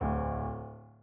sfx_猪头走路2.wav